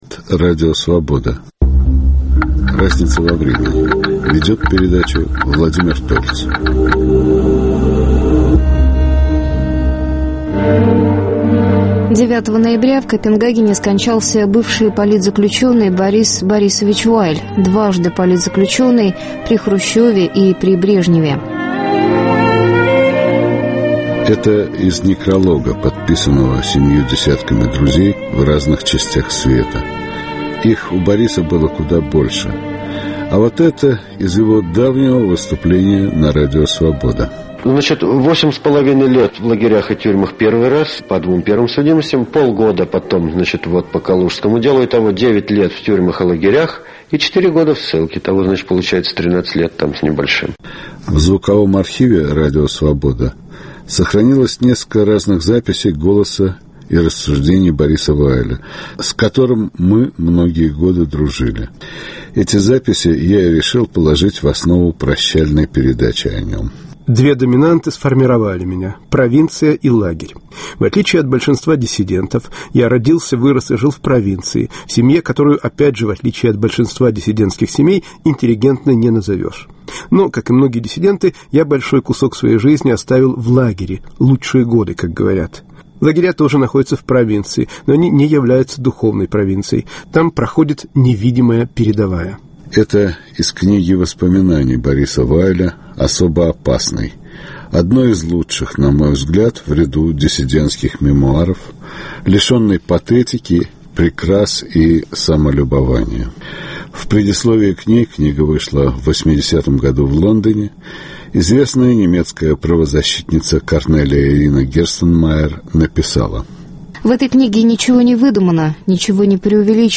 В программе передача "Памяти Бориса Вайля", в которой прозвучат фрагменты выступлений этого, скончавшегося 9 ноября 2010 г. в Копенгагене, бывшего советского политзаключенного, публициста и писателя, а также воспоминания о нем его друзей.